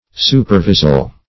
Supervisal \Su`per*vis"al\